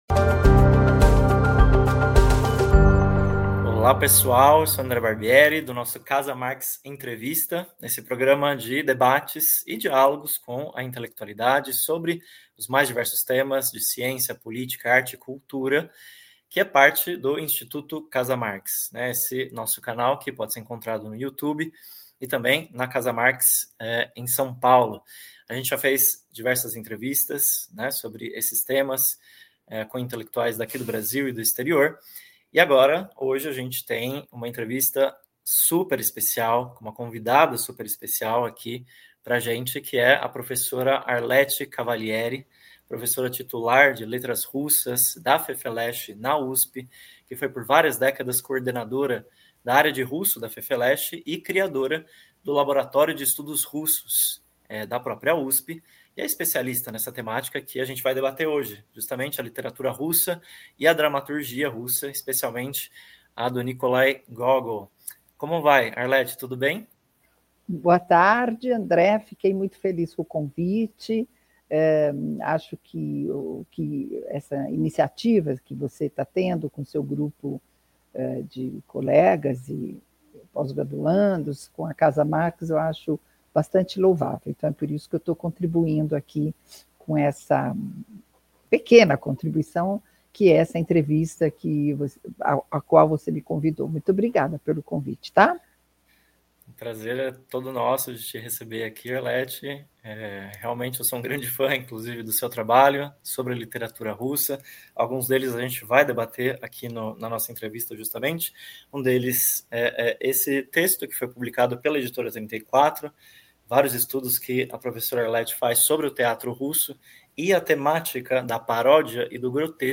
Nessa entrevista, conversamos sobre a importância da literatura russa no contexto da censura e da opressão do regime tsarista, em particular na primeira metade do século XIX, período governado pelo tsar Nicolau I, e em que viveu um dos maiores escritores modernos da Rússia, o ucraniano Nikolai Gógol (1809-1852).